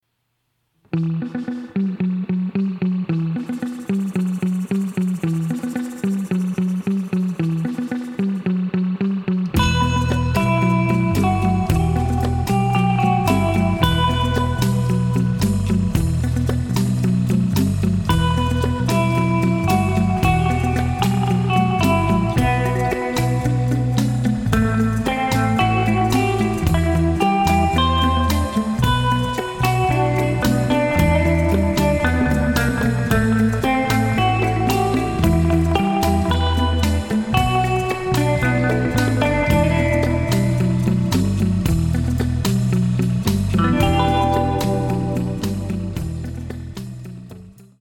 eine Uptempo-Granate mit leichtem Rockabilly-Twang-Vibe